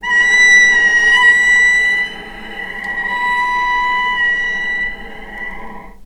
vc_sp-B5-mf.AIF